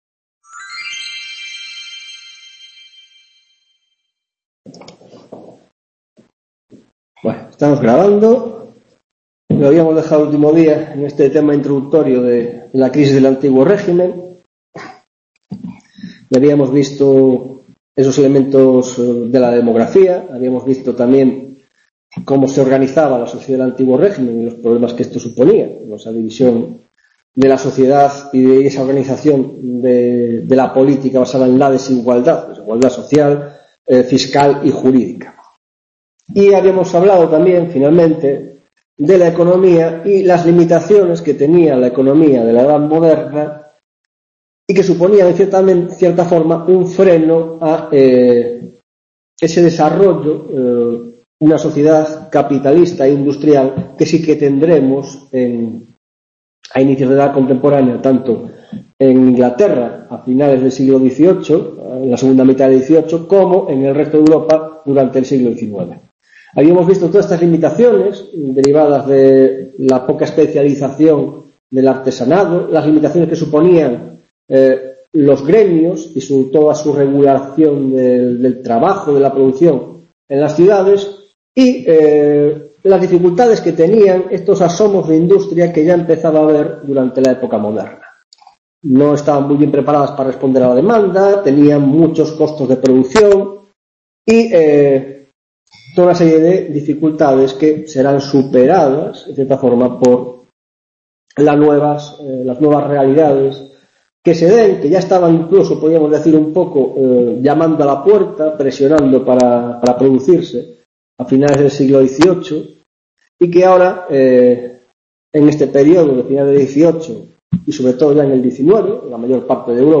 2ª tutoria de Historia Contemporánea - Introducción: La Crisis del Antiguo Régimen (2ª parte) y Revolución Norteamericana